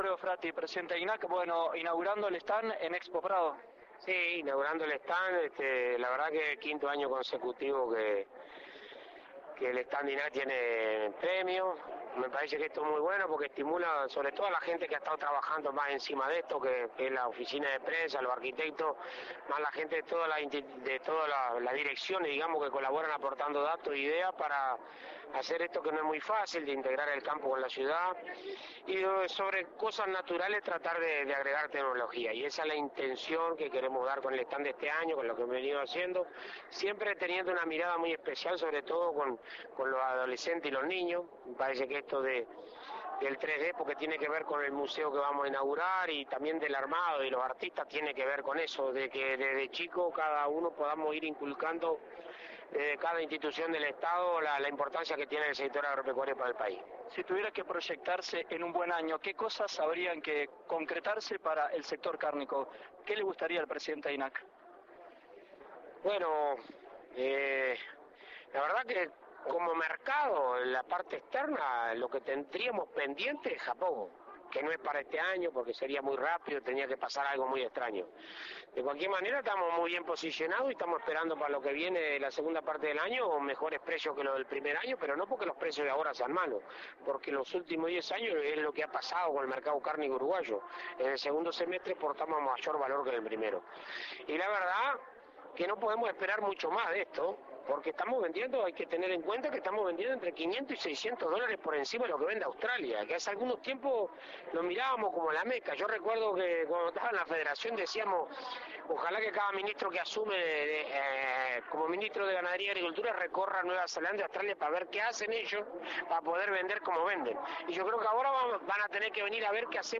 La integración de la Naturaleza con la Tecnología para apostar a un país Agrointeligente fue destacada como objetivo por el Presidente de INAC Alfredo Fratti durante la presentación del Stand en Expo Prado.
fratti_-_entrevista_-_varios.mp3